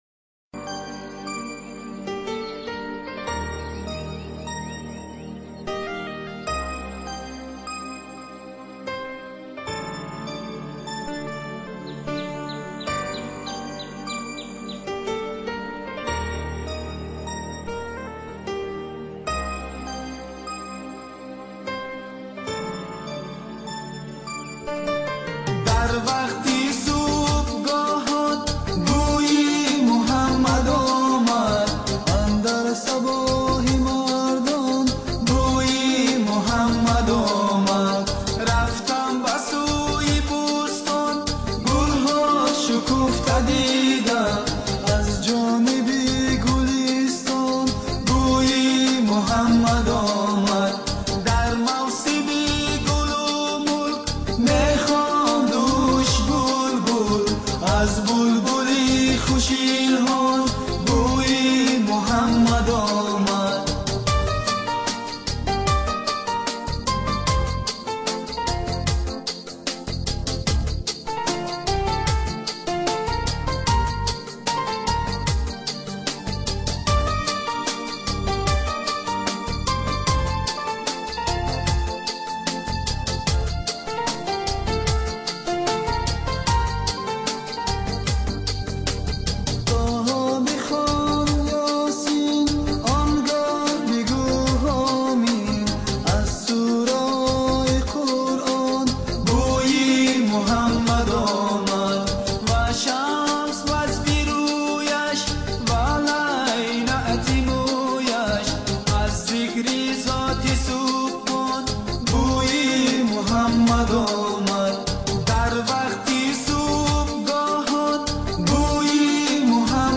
Мусиқа ва тарона
Ўзбекистон мусиқаси